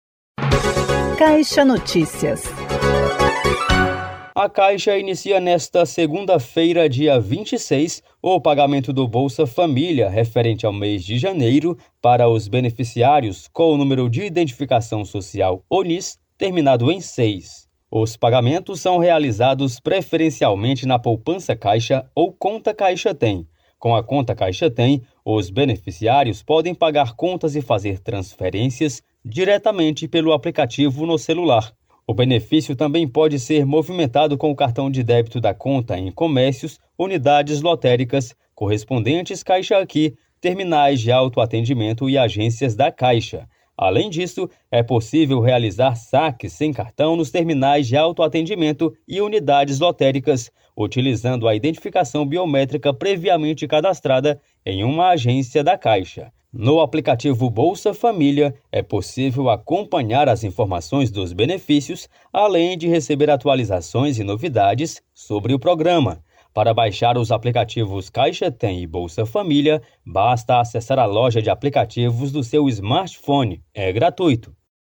Ouça o boletim e saiba mais detalhes do balanço de repasses deste ano para o Programa de Aceleração do Crescimento (Novo PAC).